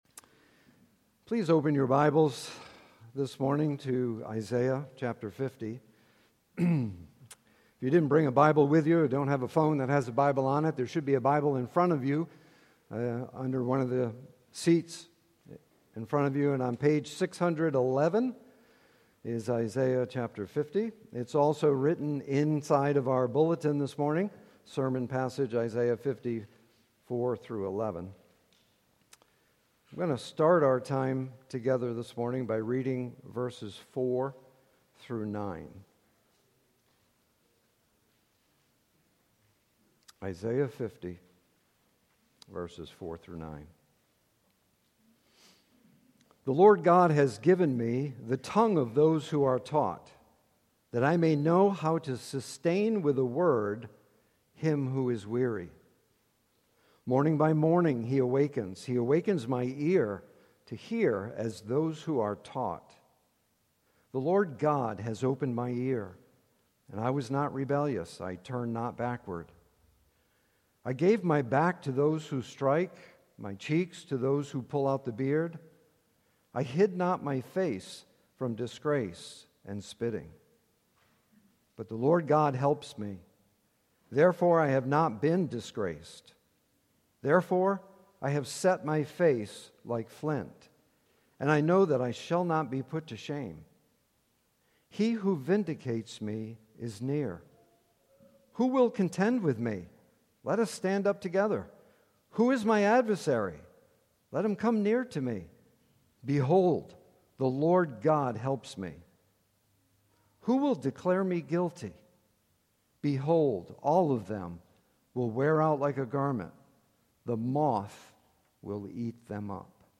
Sermons by Faith Naples